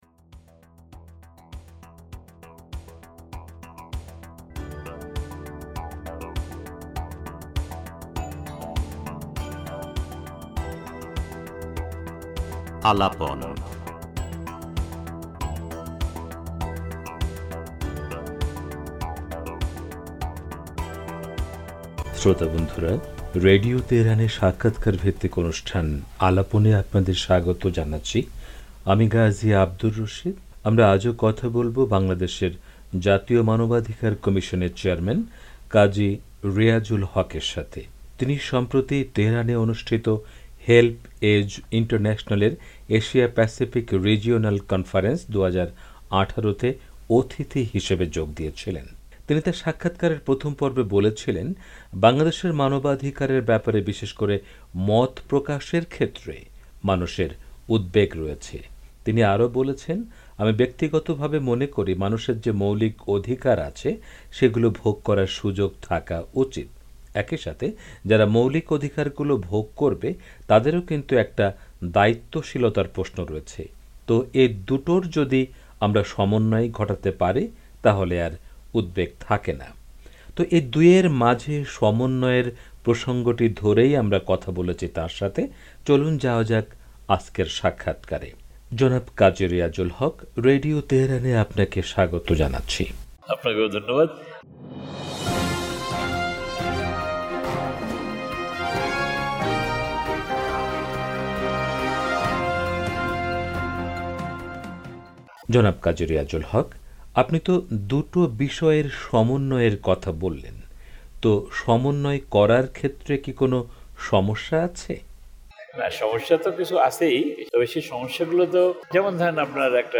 তেহরান সফরের সময় রেডিও তেহরানকে দেয়া সাক্ষাৎকারে এমন মন্তব্য করেছেন বাংলাদেশের জাতীয় মানবাধিকার কমিশনের চেয়ারম্যান কাজী রিয়াজুল হক।